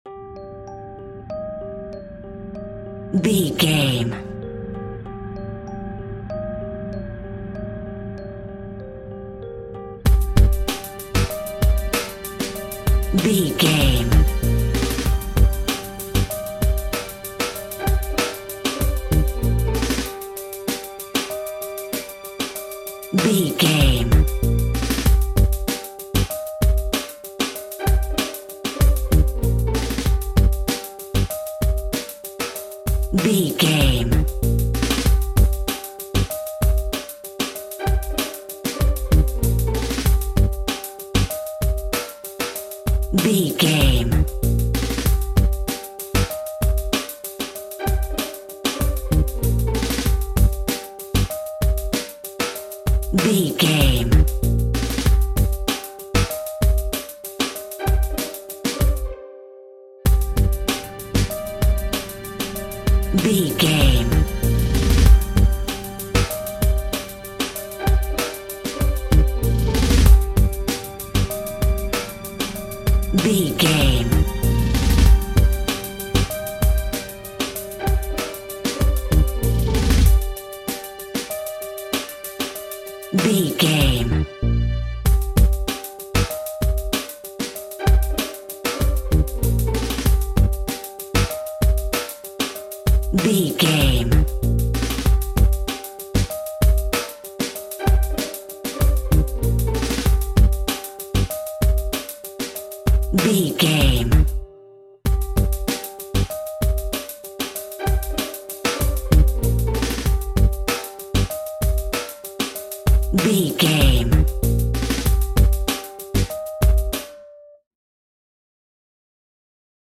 Aeolian/Minor
Funk
electronic
drum machine
synths